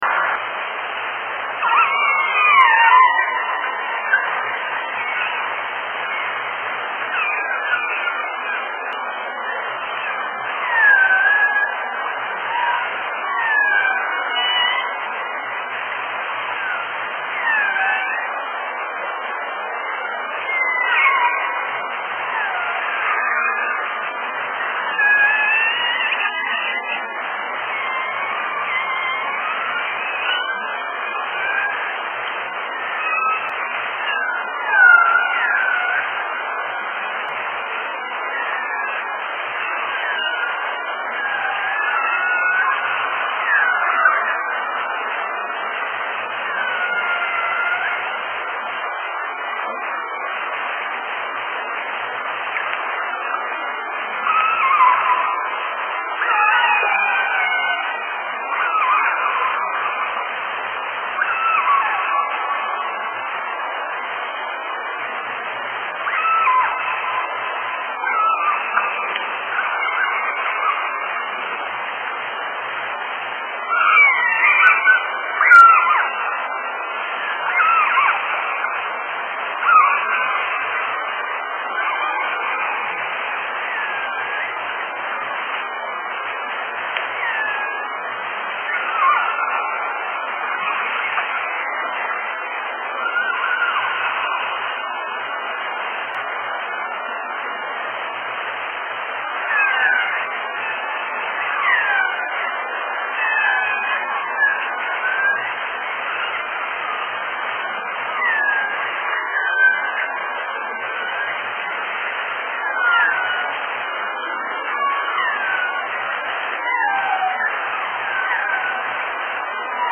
So, for those of you who don’t know, there are three different ecotypes of killer whales found in these waters.Â  The first is residents (such as the beloved Southern Resident killer whales (SRKW) that we will be focusing our research efforts on, as well as the Northern Residents).Â  The resident pods are fish-eating pods.Â  They eat primarily Chinook salmon.Â  Our SRKWs are found in three pods: J, K, and L.Â  They are known for being quite vocal (which makes them prime candidates for bioacoustic research).Â  In contrast, transients are mammal-eating.Â  They are usually found in smaller pods, and are usually much quieter then residents are.Â Â  Then there are the offshore killer whales.Â  Although believed to be genetically closest to residents, very little is known about offshores.Â  They are usually found in large groups out in more open water, and it is believed that they feed on sharks and other fish.Â  Offshores are also typically smaller than the other two ecotypes, and they make strange and hauntingÂ